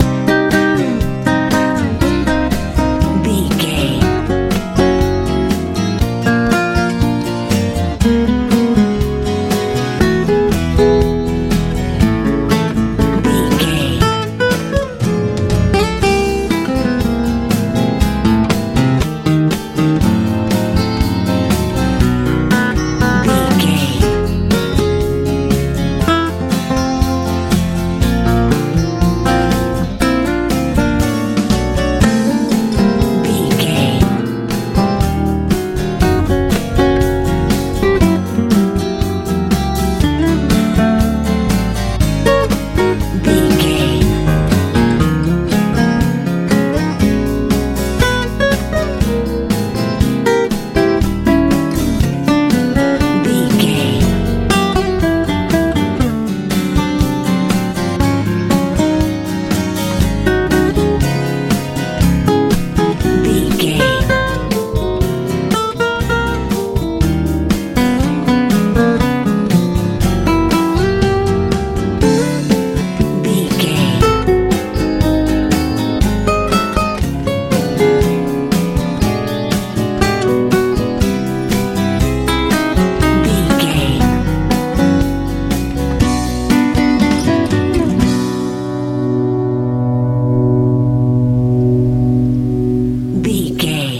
Ionian/Major
groovy
happy
melancholy
playful
acoustic guitar
bass guitar
drums